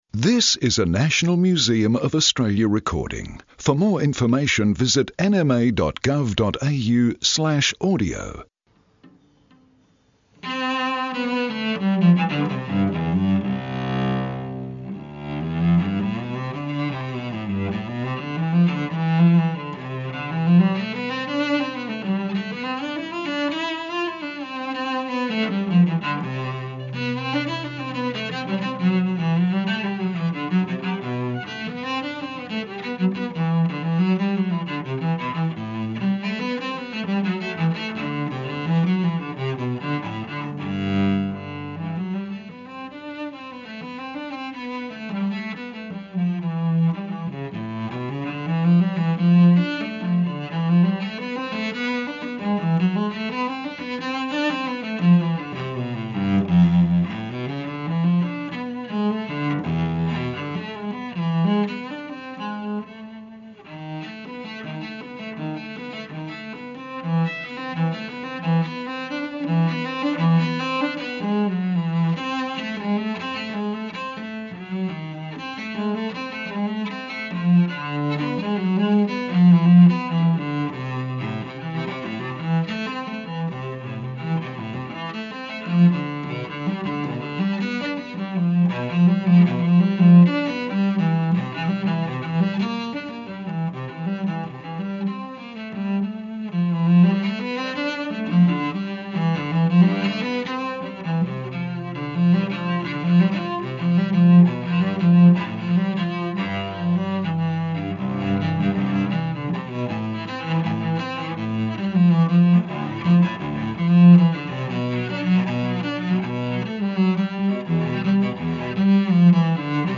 Renowned Australian cellist David Pereira plays the cello made by AE Smith in Sydney in 1953, one of the Museum’s most treasured musical instruments. Movements played: Prelude, Allemande, Courante, Sarabande, Bourrees I and II, and Gigue.